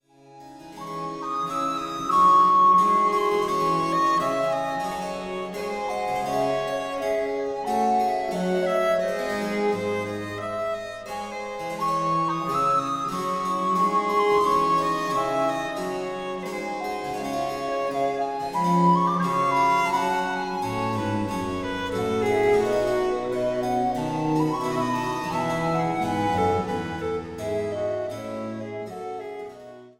die amerikanische Sopranistin